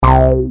error.mp3